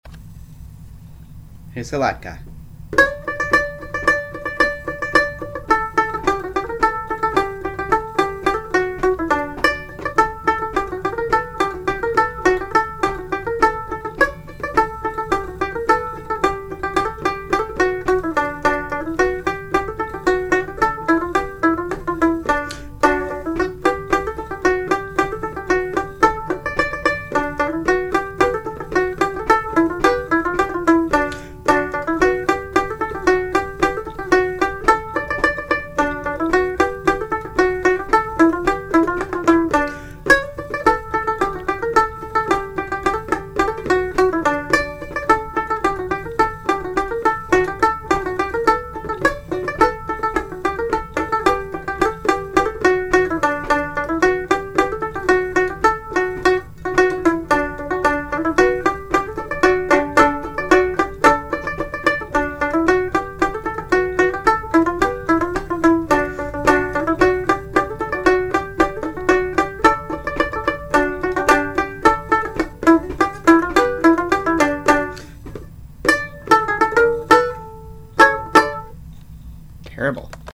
Well, for the fourth night of Hanukkah, I’m posting a scratch track that I made very simply (recorded straight into the computer’s built-in mic) for the Ramblers to be able to hear and practice with when we were learning it.
The instrument you’re hearing is the skin-head banjo ukulele I picked up some years ago at an antique shop in Kewaskum, Wisconsin.